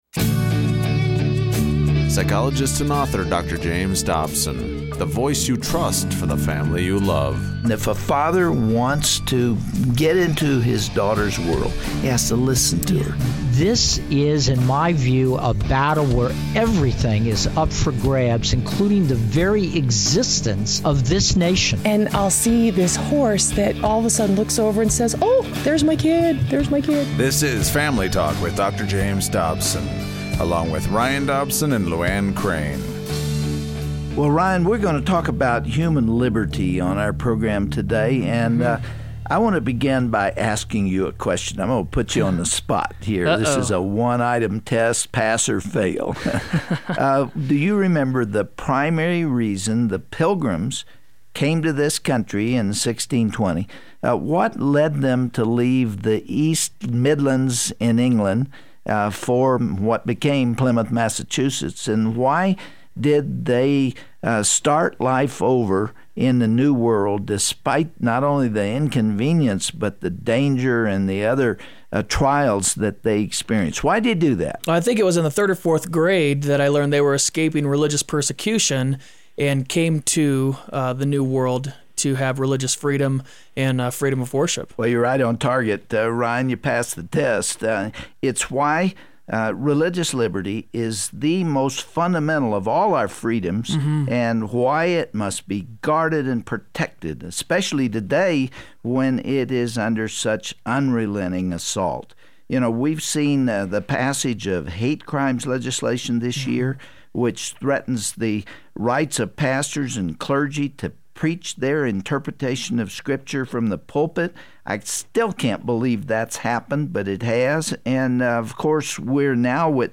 Dr. James Dobson speaks with Chuck Colson and Robert George about the subtle, but very dangerous, distinction being made by elected officials between the freedom to worship privately as we please, and the right to practice our religion publicly.